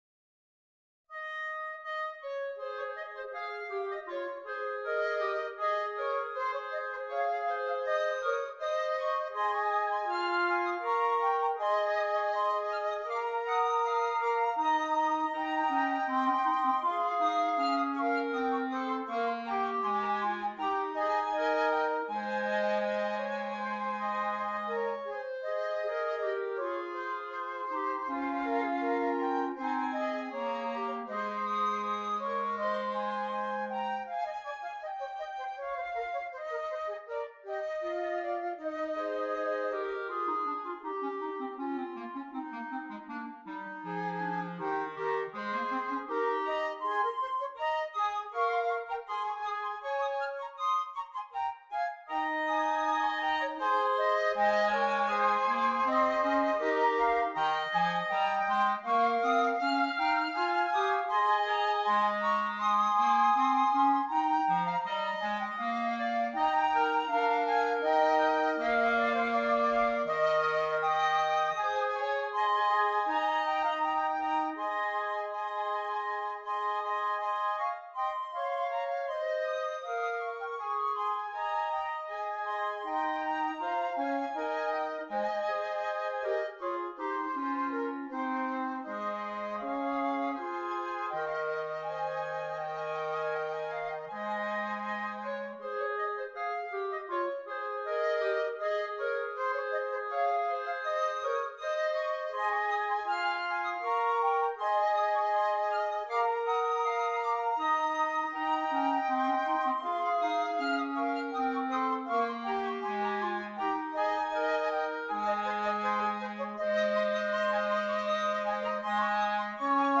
Voicing: Woodwind Quartet